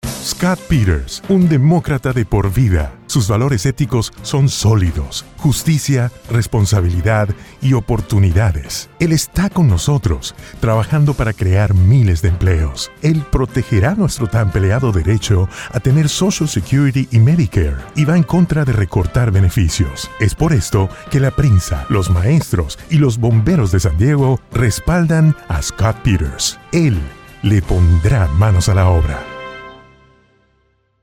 Male VOs